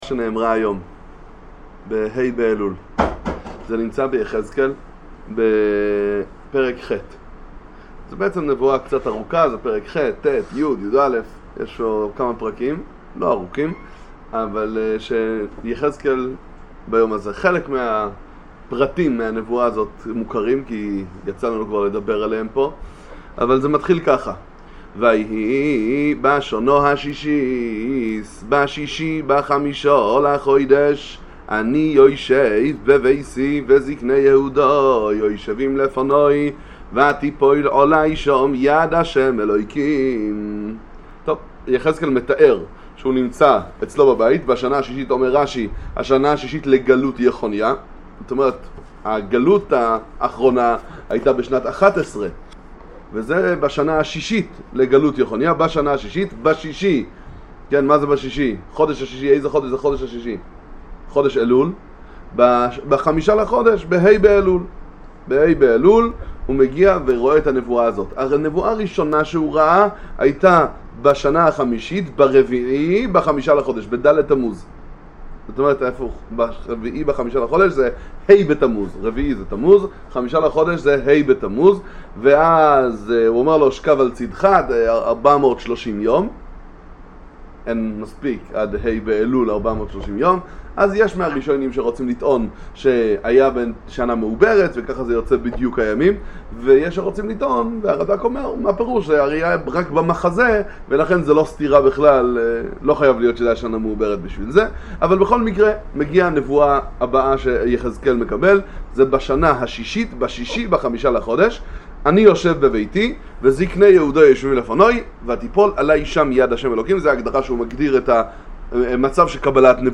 נבואת יחזקאל, שיעורי תורה בנביאים וכתובים